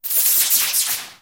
Подборка включает разные варианты звучания, от глухих разрядов до шипящих импульсов.
Разряд шаровой молнии